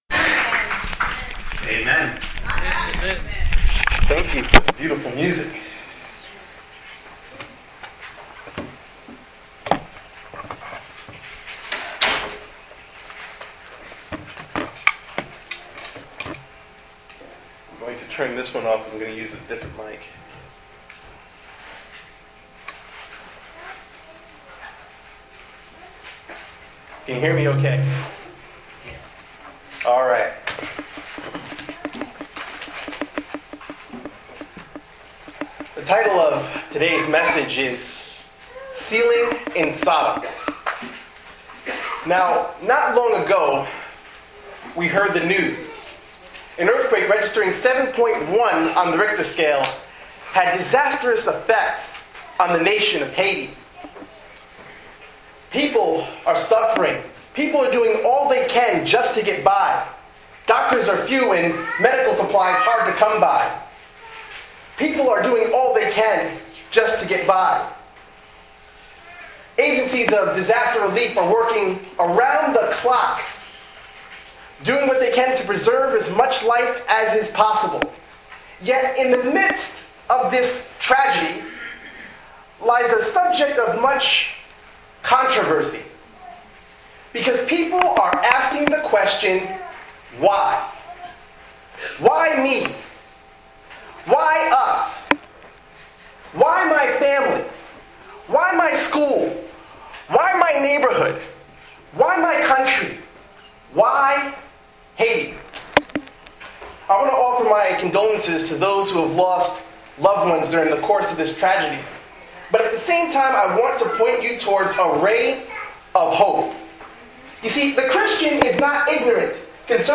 Sermon touches Bible prophecy: the sealing and the 7 last plagues.